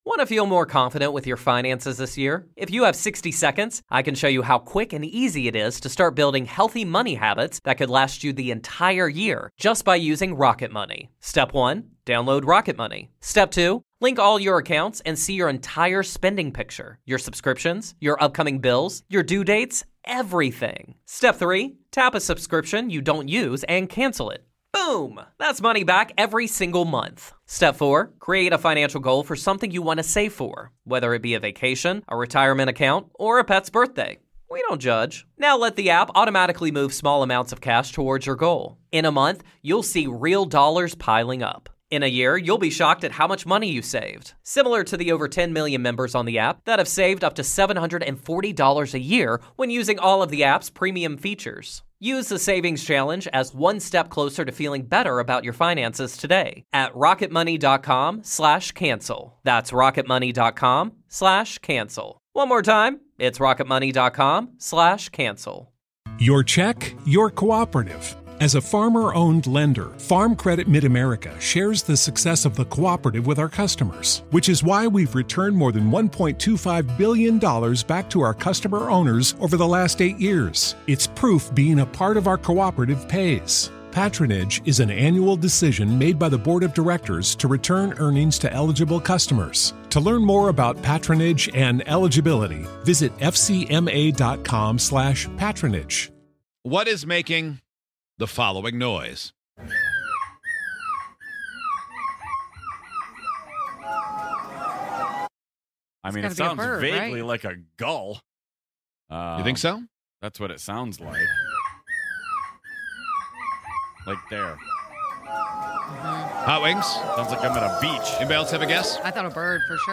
On today's show, a boy won a seagull screeching contest and, naturally, we had to give it a go. We found out one show member is surprisingly good at impersonating a seagull.